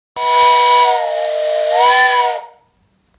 train-whistle.mp3